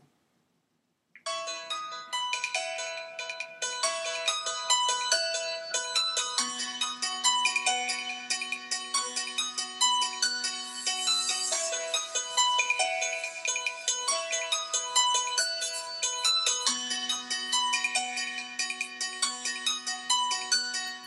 It was dark when you went to bed at 1:00 AM and set the alarm on your Android phone.
alarm.mp3